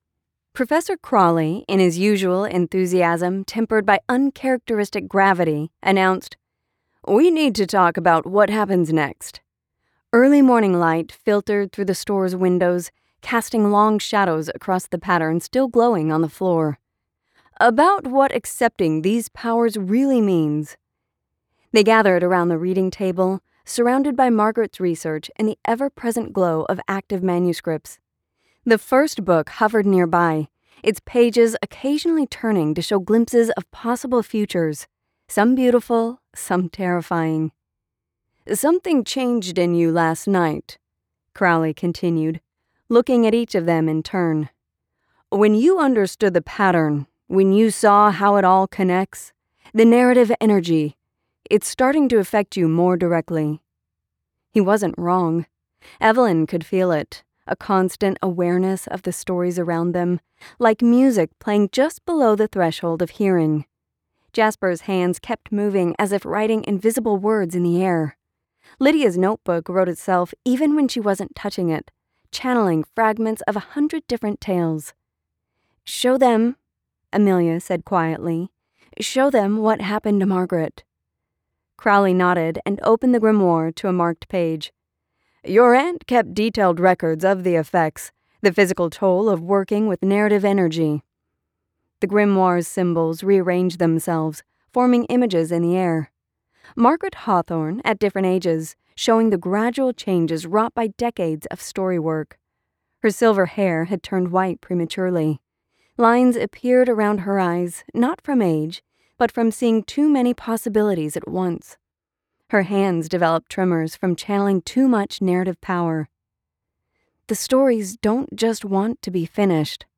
Female, North American neutral accent, with an adult/young-adult vocal sound. Pitch is not too high and not too low, with a smooth vocal quality. Can be upbeat, happy and conversational or polished and professional depending on the read and desired tone.
Audiobooks
Young Adult Fiction